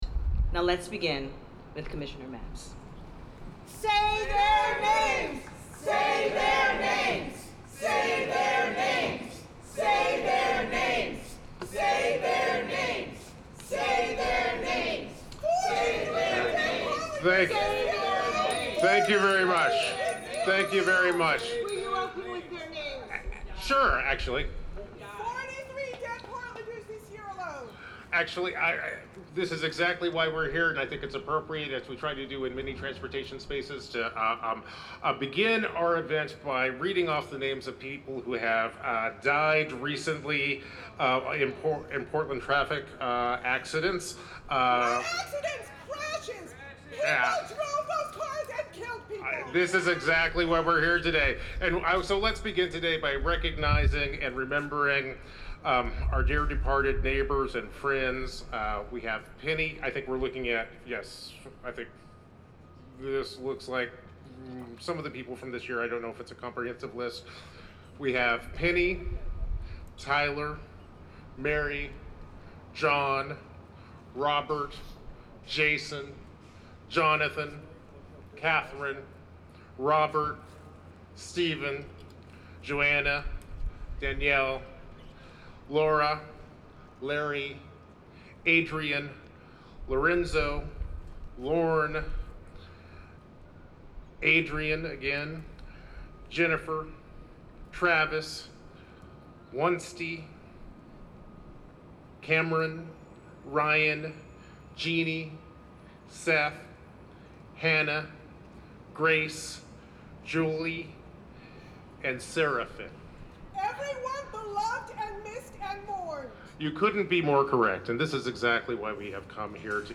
Protestors shout down officials at road safety press conference
(Audio clip above is Commissioner Mapps speaking with protestors shouting.)